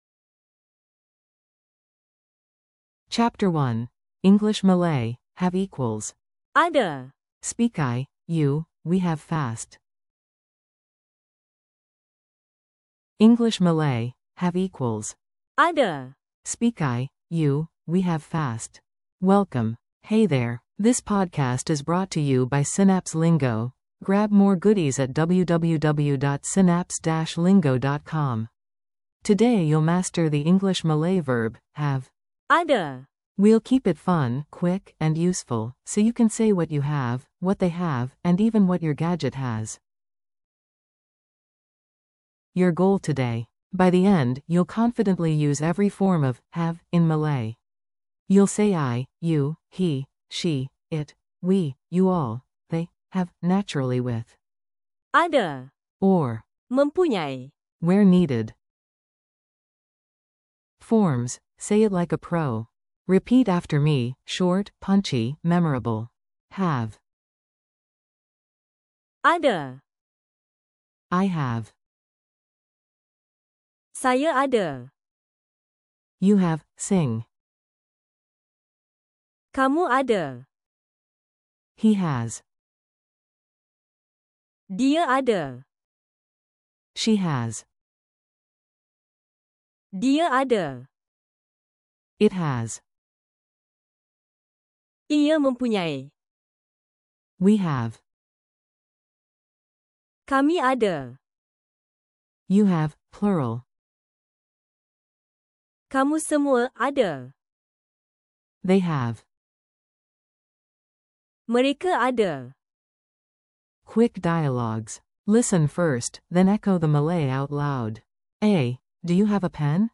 English → Malay
Audio for repeating & practicing